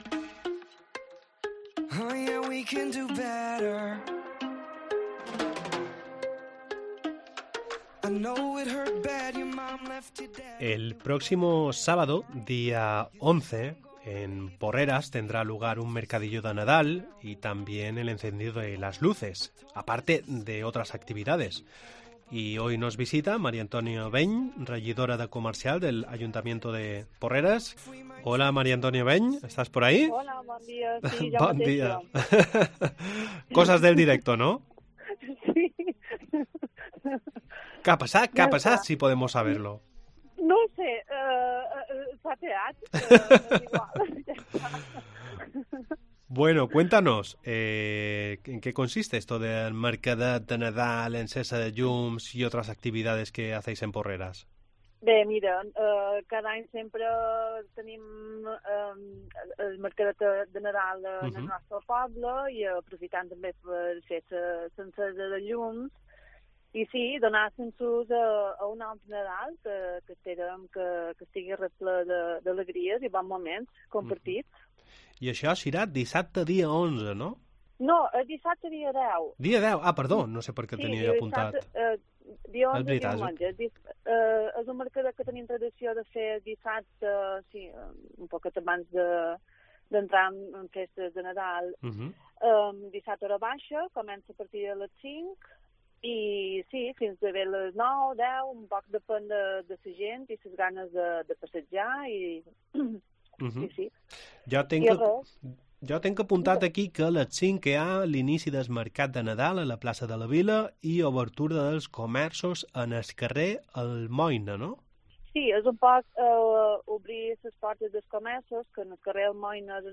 Hoy nos visita María Antonia Veñy, regidora de comercio del ayuntamiento de Porreras, para hablar sobre el mercadet de nadal y el encedido de lues que tendrá lugar el sábado 10